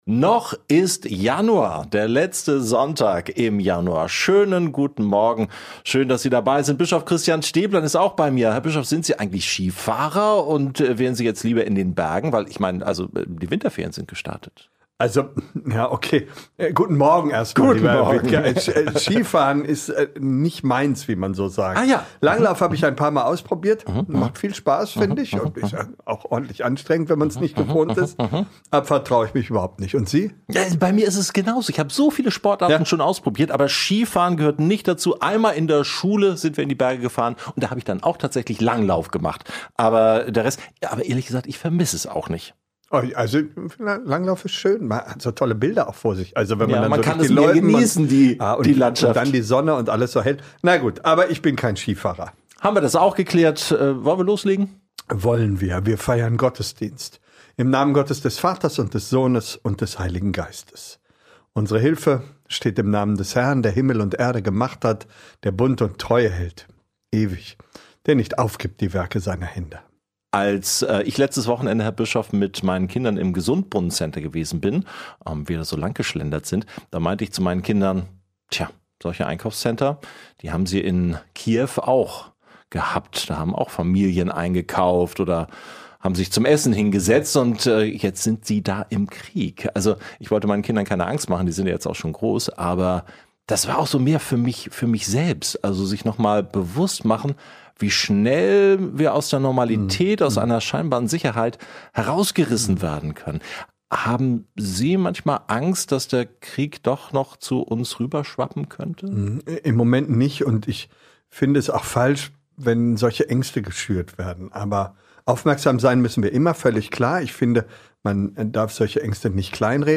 Der Gottesdienst im Gespräch ist thematisch ganz im Hier und Jetzt. Bei allem Zwiespalt bezüglich eines Einsatzes von Kriegswaffen geht es im Predigttext um die klaren Momente in unserem Leben und um die Klarheit, die Jesus in die Welt gebracht hat."